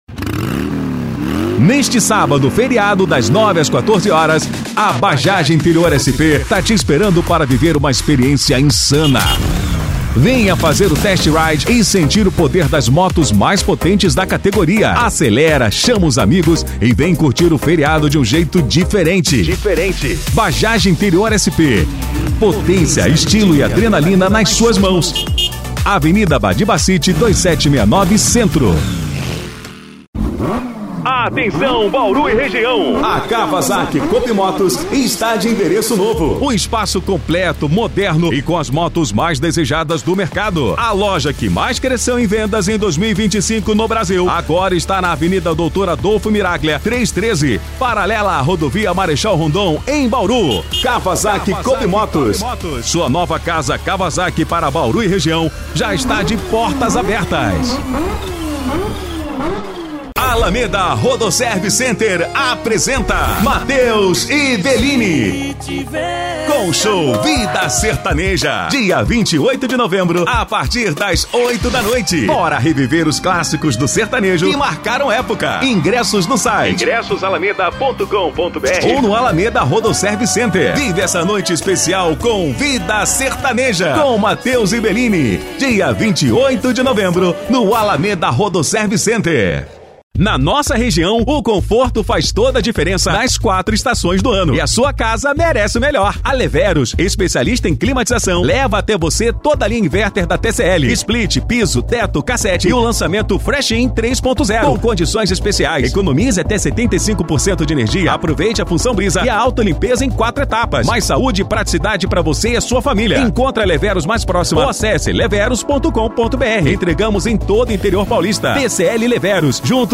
Spot Comercial
Impacto
Animada